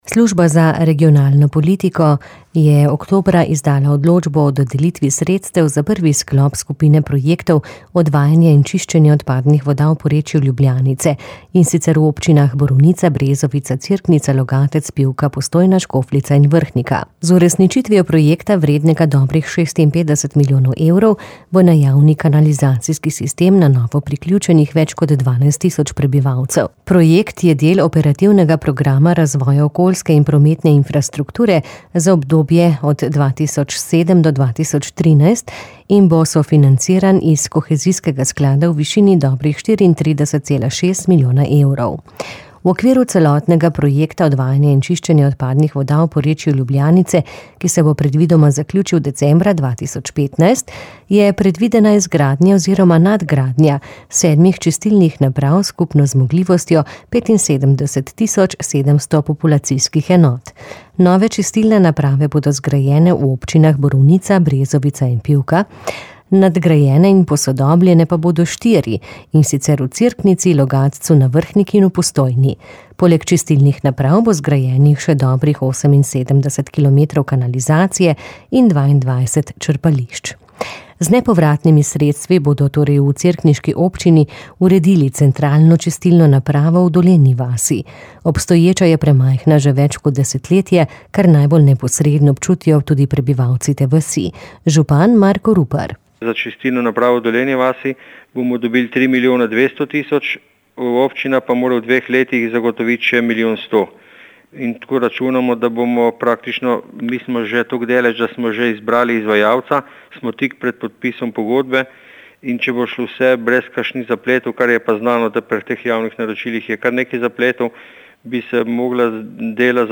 Z nepovratnimi sredstvi bodo v cerkniški občini nadgradili novo centralno čistilno napravo v Dolenji vasi. Občina Cerknica pa v drugem krogu projekta računa na nepovratna sredstva tudi za čistilno napravo na Rakeku, je povedal župan Marko Rupar.